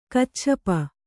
♪ kacchapa